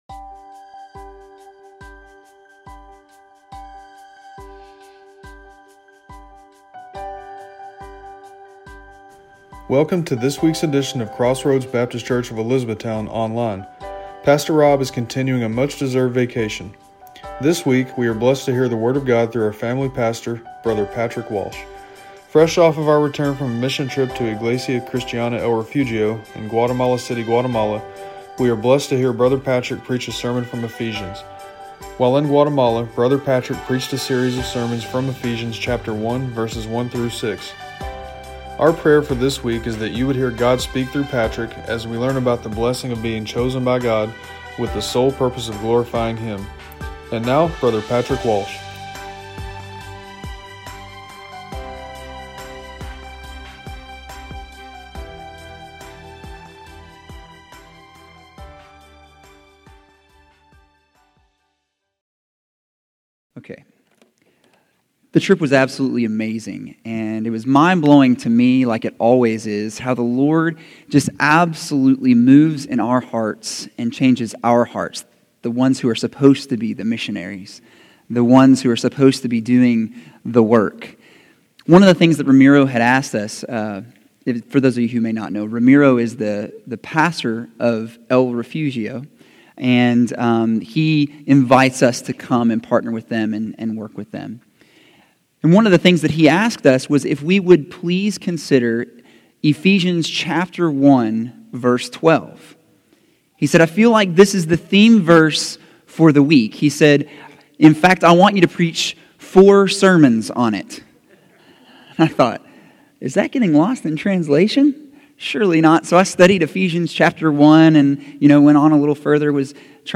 Individual Sermons